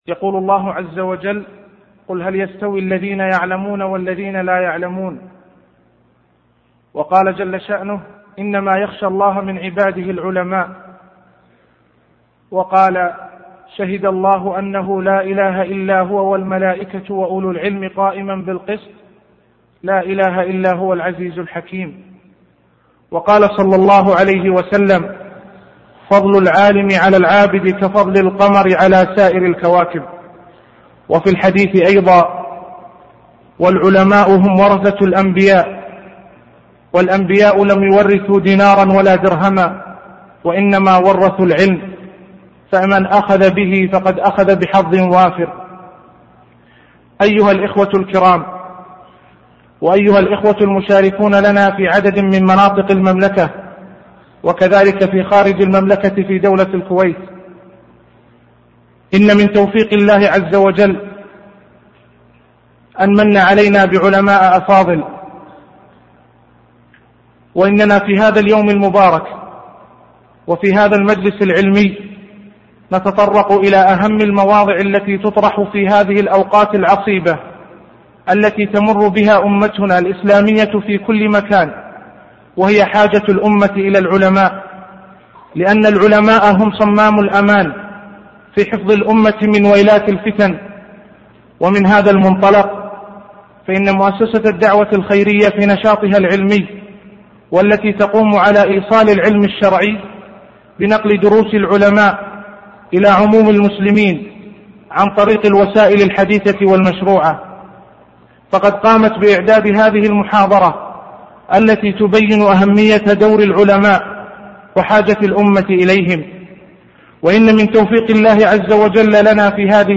محاضرة صوتية نافعة